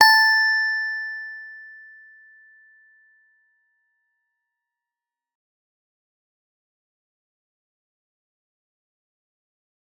G_Musicbox-A5-f.wav